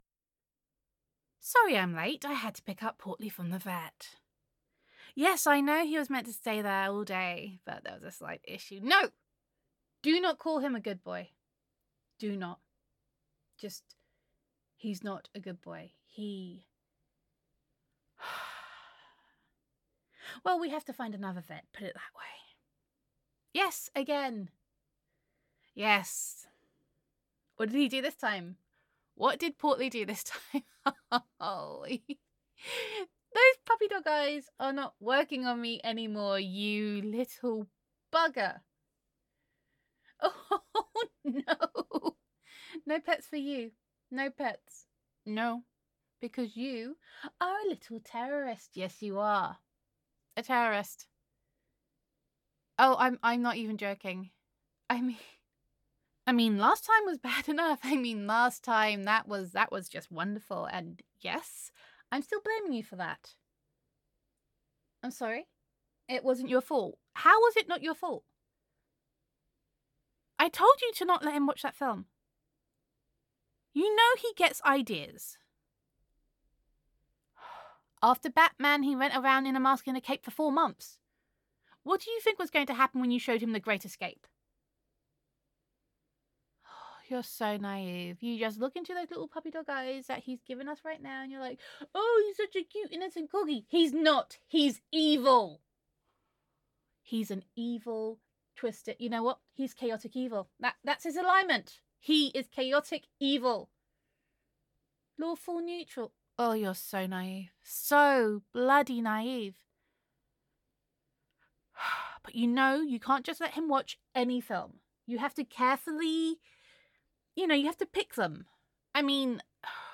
[F4A] Portly? More Like Fatty [He Is Not a Good Boy][a Corgi With a Bad Reputation]